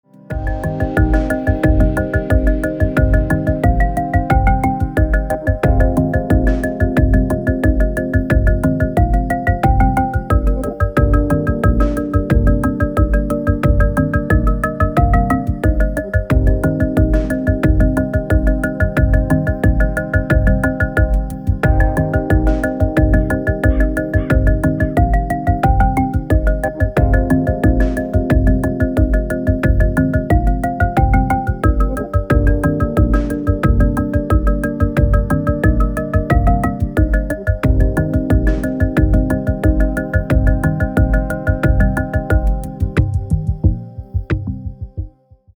EDM
электронная музыка
спокойные
без слов
Downtempo
звуки птиц
этнические
Ambient